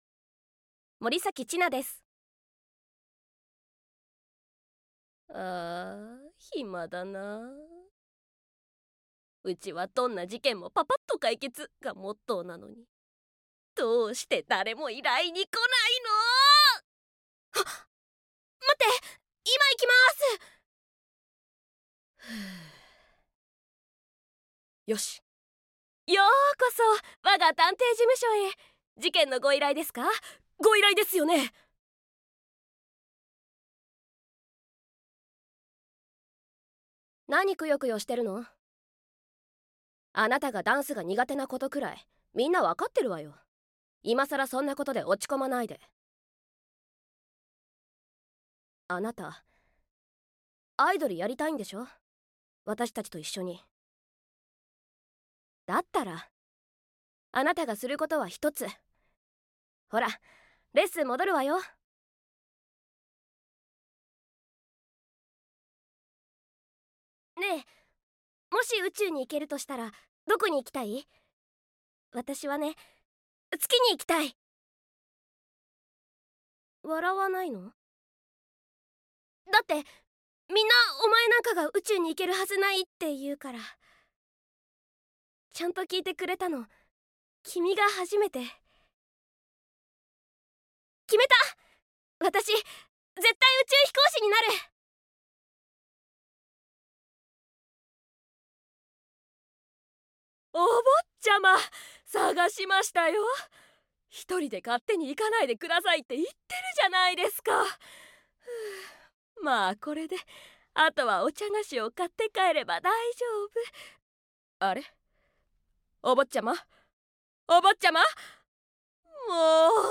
サンプルボイス
方言 博多弁